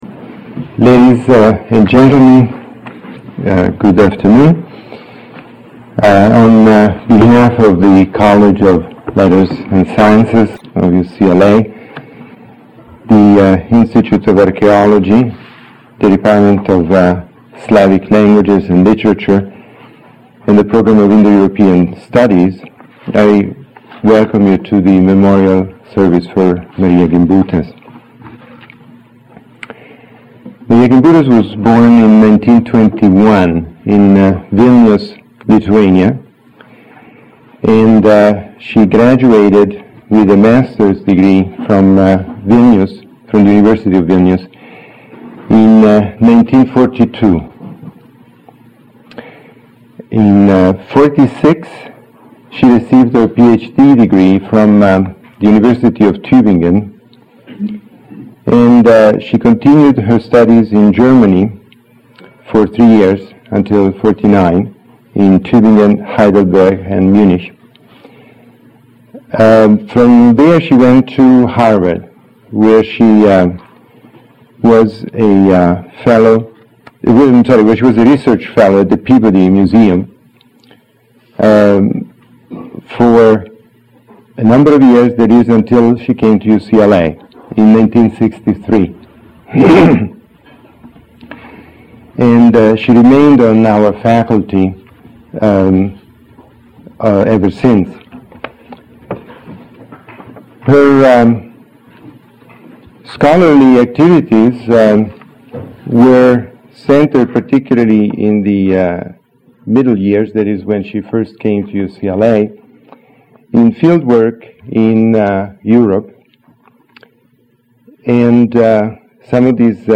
A presentation for a memorial in honor of Marija Gimbutas
A presentation for a memorial in honor of Marija Gimbutas A presentation I gave in honor of Marija Gimbutas at UCLA. The audio (12.49 minutes long) is not very clear at the beginning, but it becomes better.